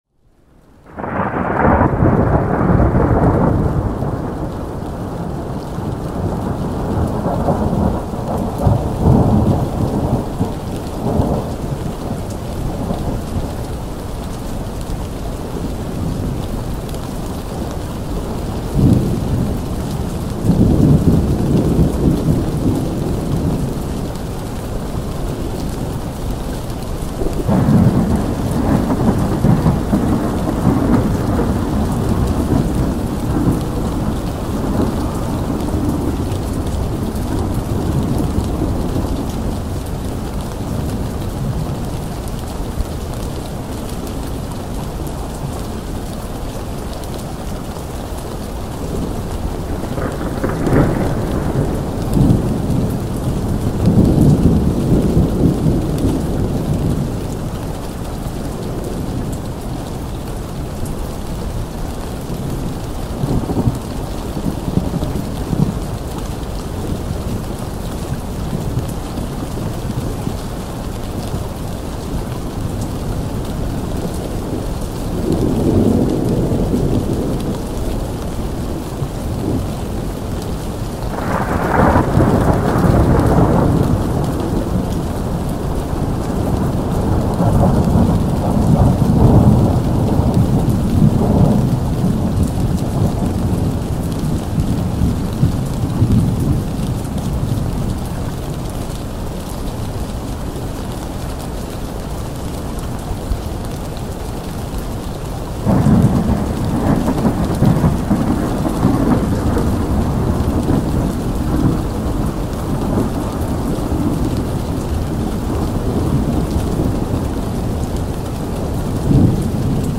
Cabin Window Storm – Rain and Thunder for Deep Sleep
Every episode of Rain Sounds is carefully crafted to deliver high-quality ambient rain recordings that promote deep sleep, reduce anxiety, and enhance mindfulness.
Whether you love the steady rhythm of a night storm, the calming drip of rain on a window, or the soft patter of distant thunder, Rain Sounds brings nature&rsquo